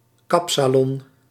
A kapsalon (fonetikusan: ['kɑpsɑlɔn]
Nl-kapsalon.ogg